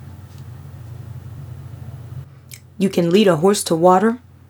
It sounds like you’re mixing cement in there with you.
I prepared a sample where I made the noise at the beginning worse on purpose so you could hear it.